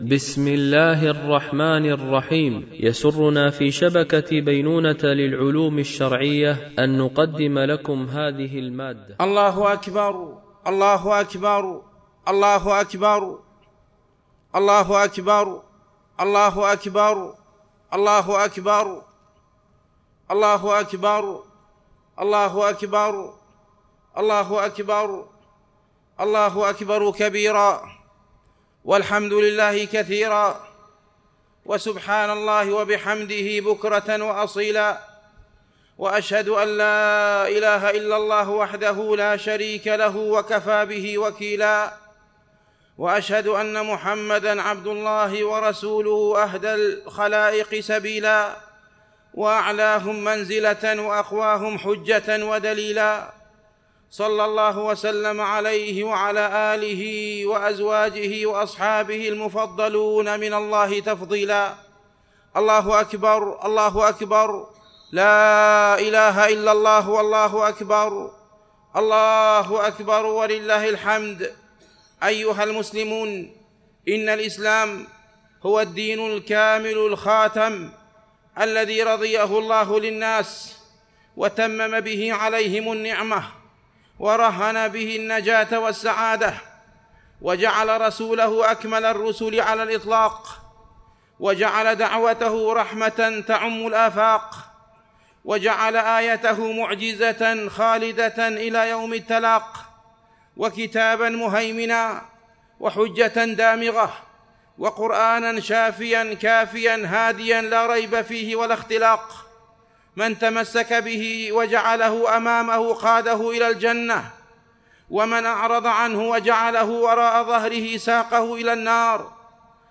خطبة عيد الفطر لعام 1444 هـ